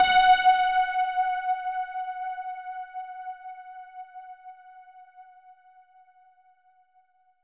SOUND  F#4.wav